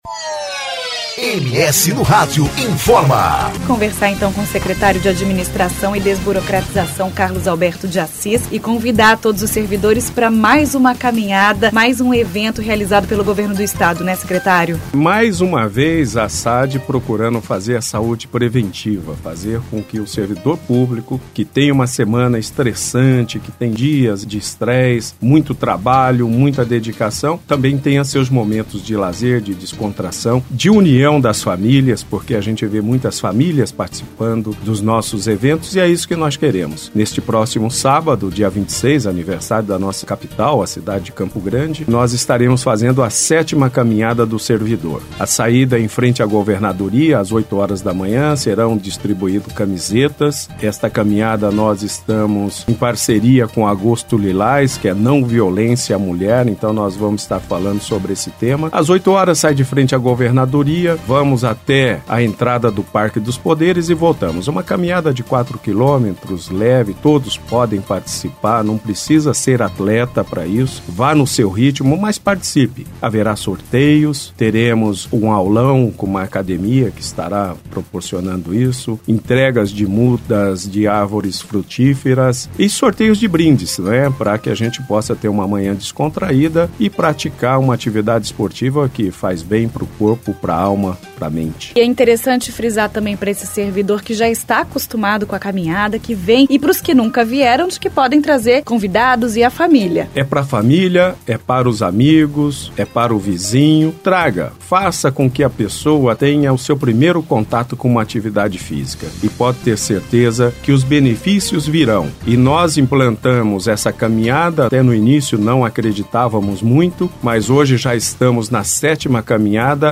Entrevista: Carlos Alberto de Assis - secretário de Administração e Desburocratização.